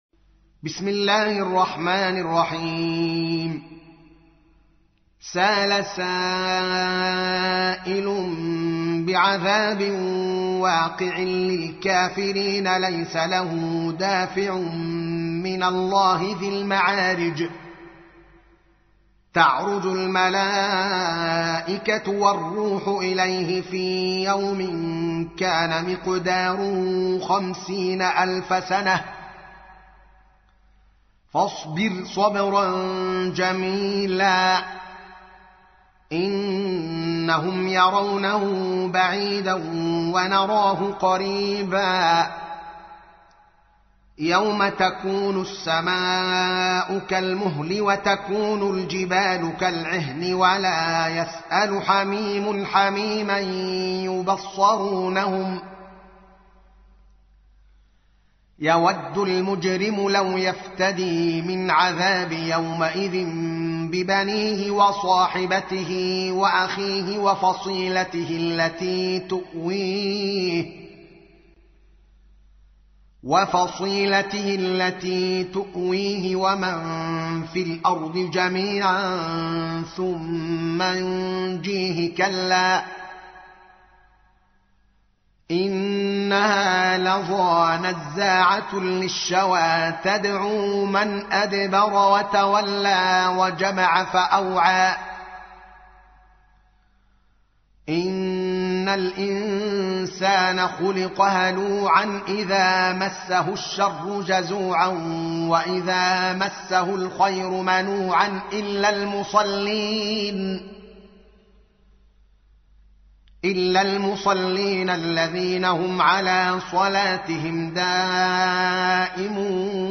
تحميل : 70. سورة المعارج / القارئ الدوكالي محمد العالم / القرآن الكريم / موقع يا حسين